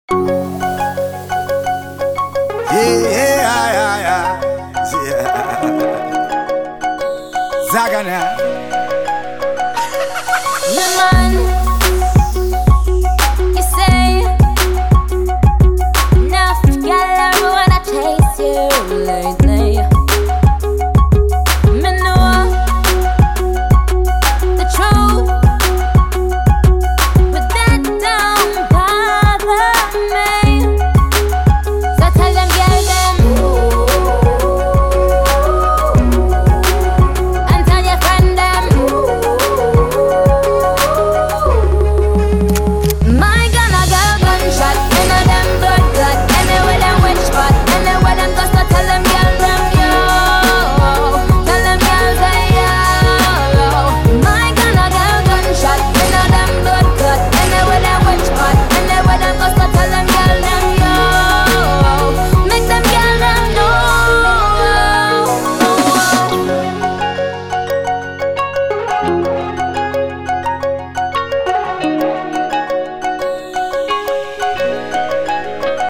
• Качество: 192, Stereo
красивые
спокойные